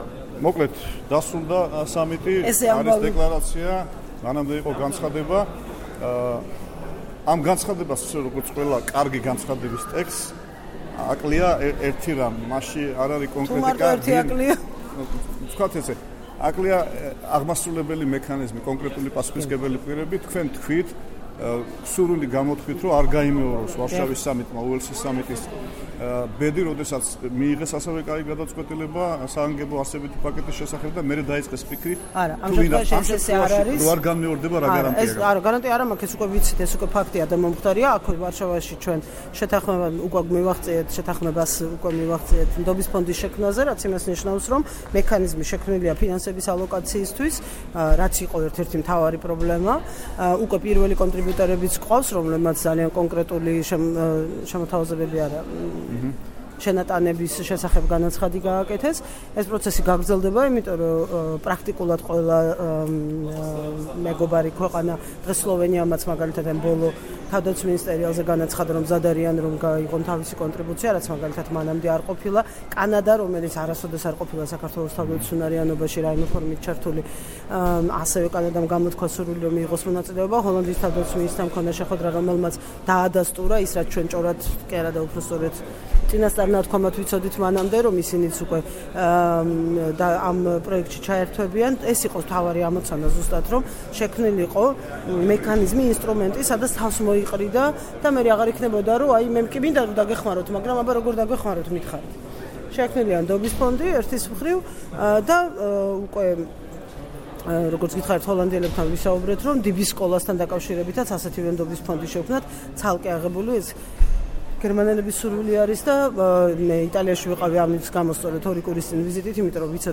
საუბარი თინა ხიდაშელთან